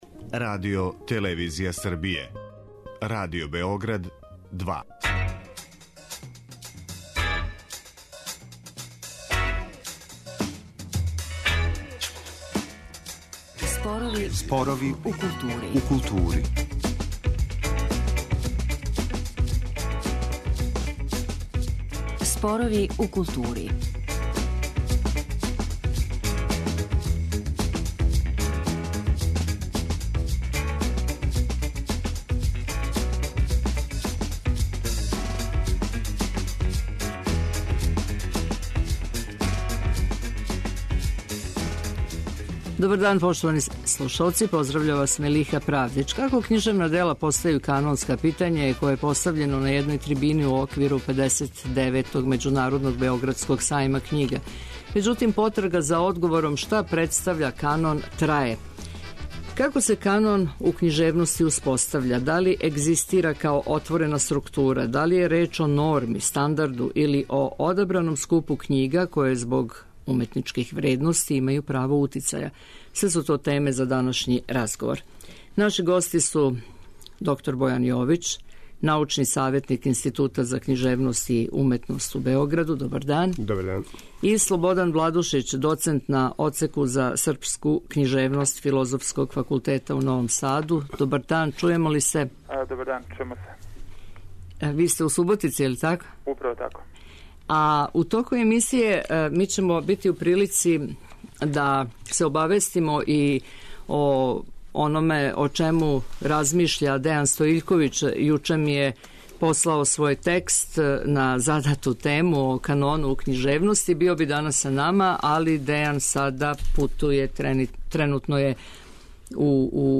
Да ли је реч о норми, стандарду или о одабраном скупу књига које због уметничких вредности имају право утицаја... - теме су за разговор.